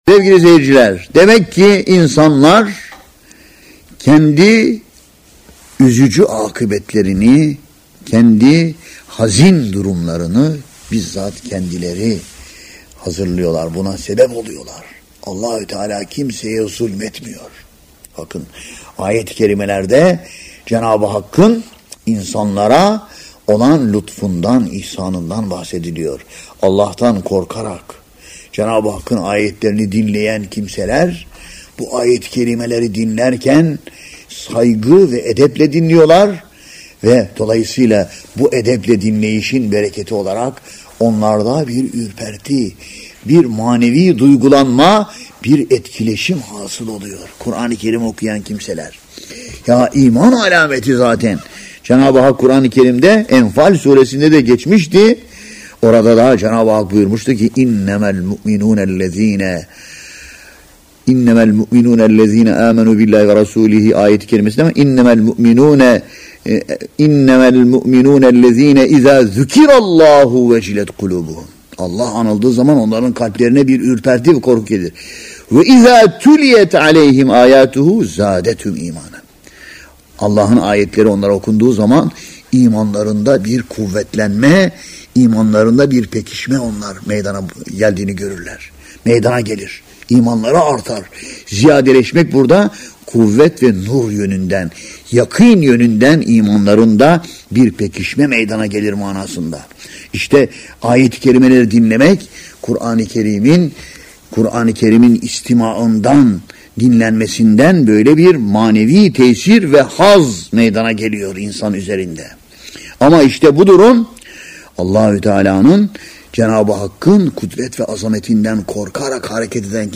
Tefsir